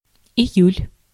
Ääntäminen
Vaihtoehtoiset kirjoitusmuodot (vanhentunut) Julye Ääntäminen US : IPA : [dʒəˈlaɪ] Tuntematon aksentti: IPA : /d͡ʒʊˈlaɪ/ IPA : /dʒuˈlaɪ/ Lyhenteet ja supistumat JUL Jul.